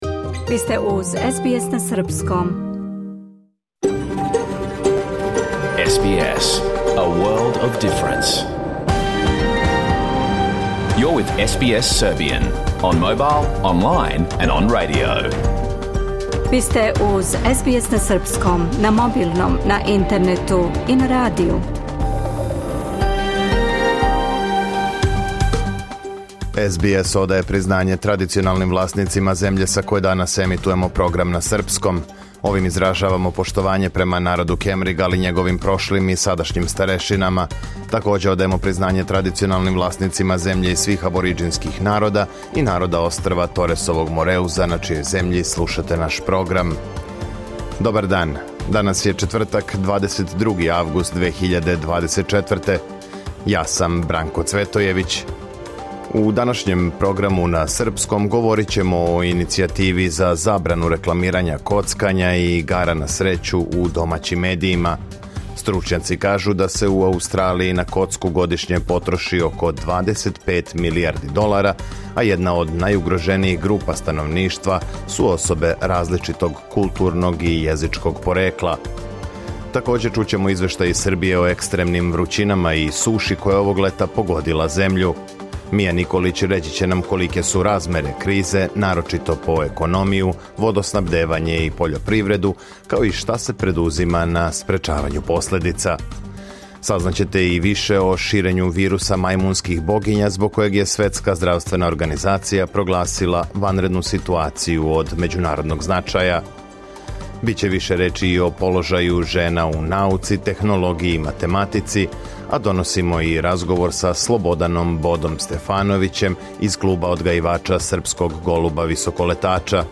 Програм емитован уживо 22. августа 2024. године
Уколико сте пропустили данашњу емисију, можете је послушати у целини као подкаст, без реклама.